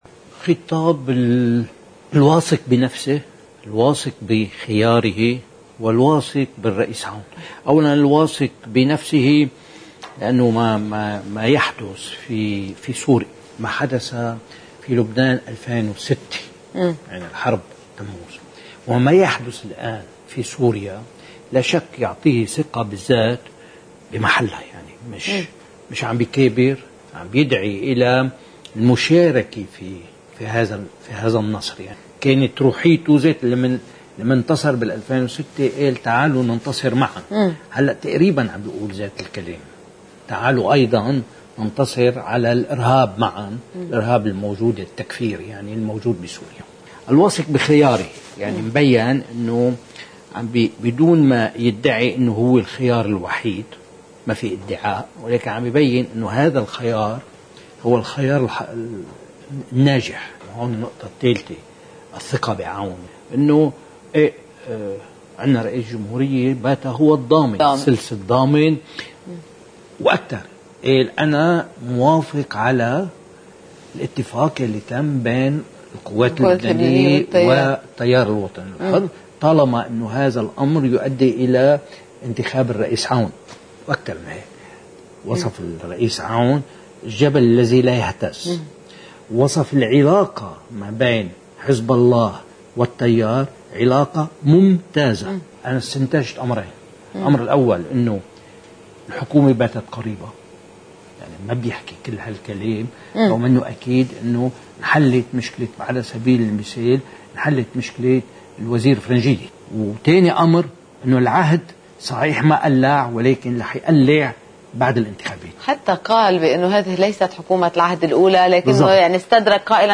مقتطفات من حديث الوزير السابق كريم بقرادوني لقناة الجديد: